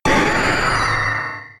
Cri de Méga-Roucarnage K.O. dans Pokémon Rubis Oméga et Saphir Alpha.